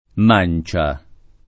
mancia
mancia [ m # n © a ] s. f.; pl. -ce